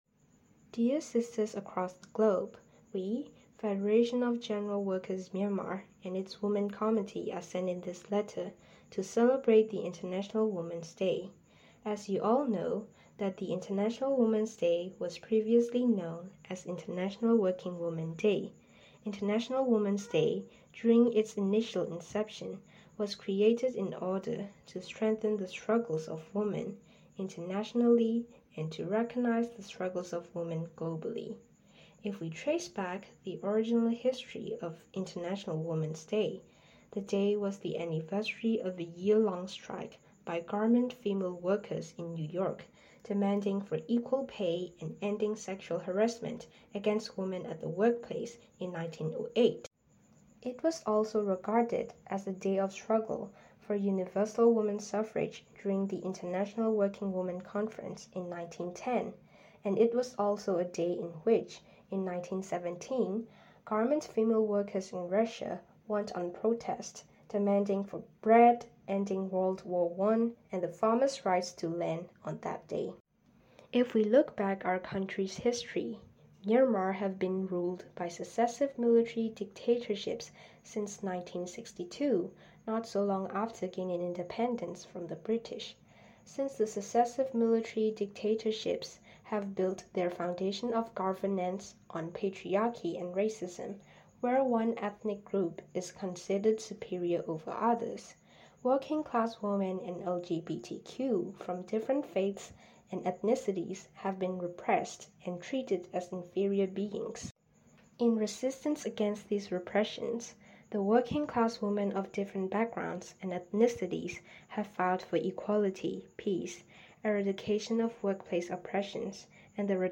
• That input was followed by a message recorded by our friends of the Federation of General Workers Myanmar (FGWM). The original audio file (in English) was played and each paragraph translated into German: